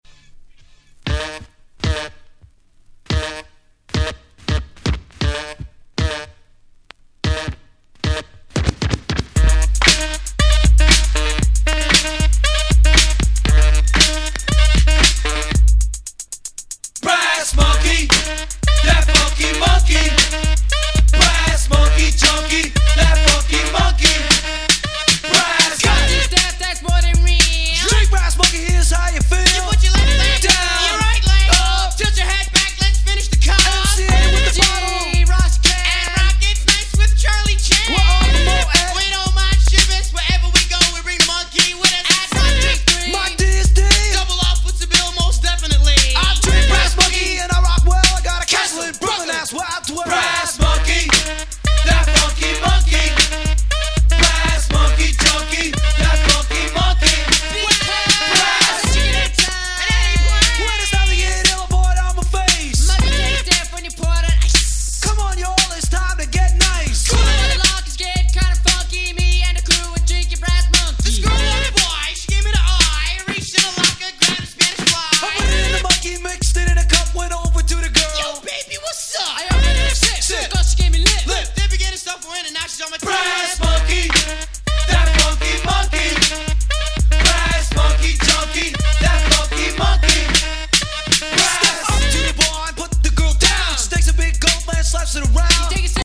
Rock調の楽曲あり、ClassicなOld Schoolありの飽きの来ない最強の名盤！！